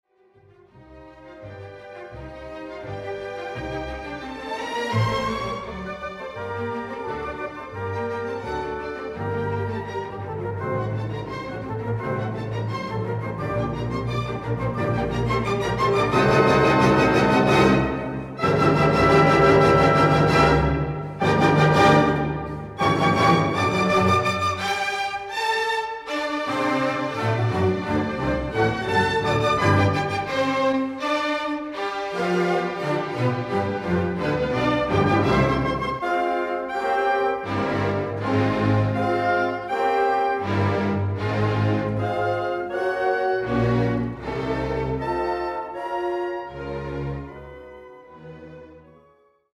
Artysta niezbyt dobrze odnajdywał się w pracy w studio i to nagranie niestety tylko to potwierdza.
Efekt jest łudząco podobny – tempa są konsekwentnie powolne, akcenty zazwyczaj zaznaczone bardzo słabo, drugorzędne detali zazwyczaj umykają a dobra artykulacja to coś, co zdarza się innym.
Wilhelm Furtwängler, Wiener Philharmoniker, 1954, I – 8:36 (P), II – 11:21, III – 6:03 (P), IV – 9:42 [35:42], Warner